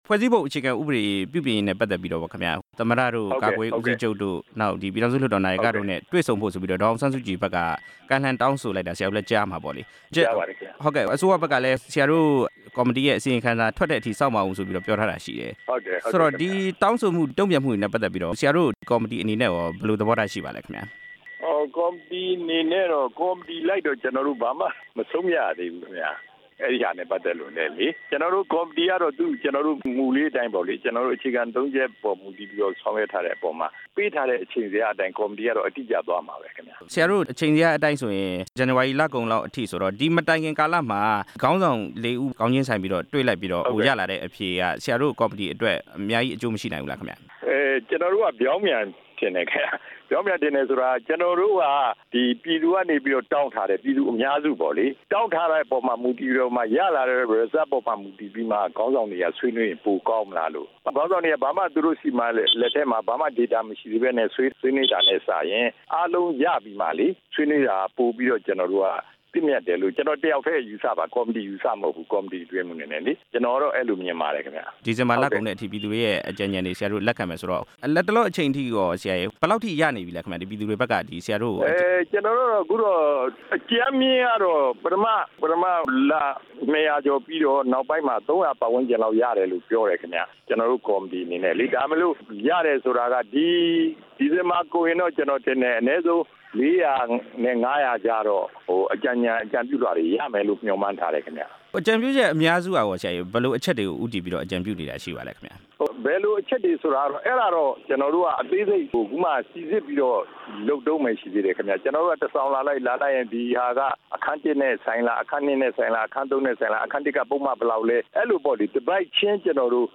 ပြည်သူ့လွှတ်တော် ကိုယ်စားလှယ် ဦးအေးမောက်နဲ့ မေးမြန်းချက်